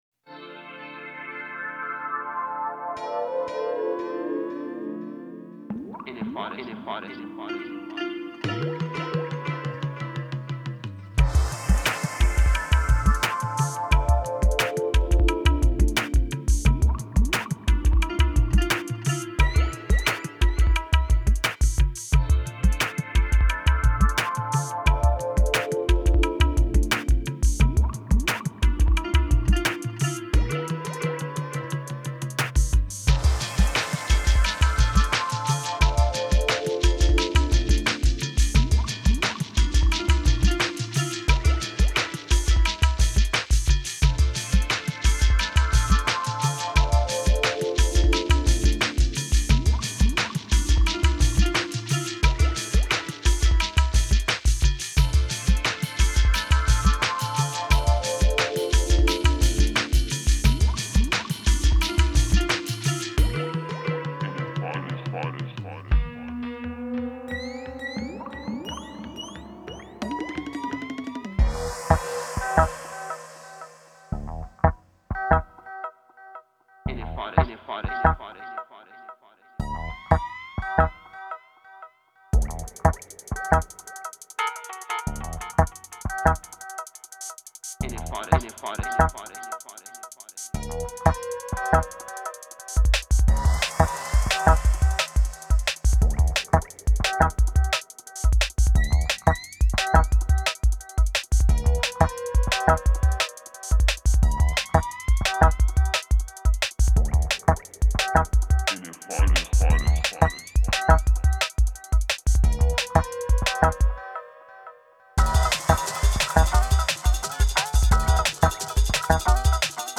Genre: Dub, Downtempo, Tribal.